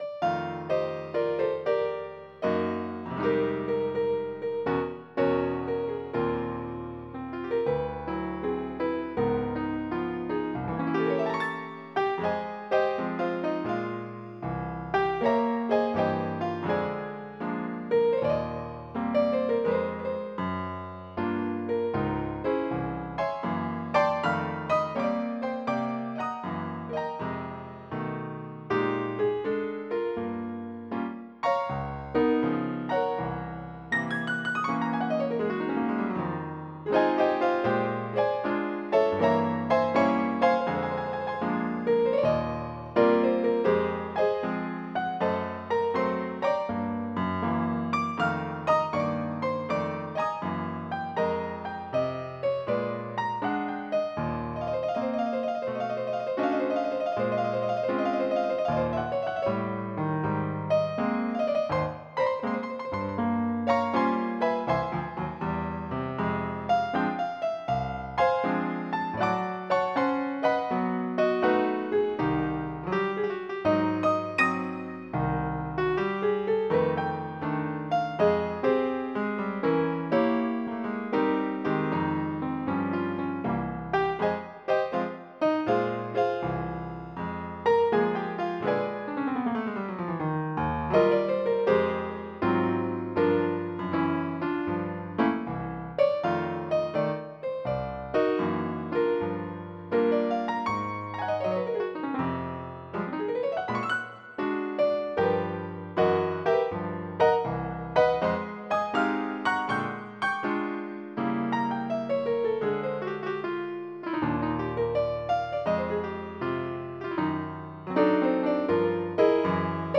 MIDI Music File
Type General MIDI
jazz72.mp3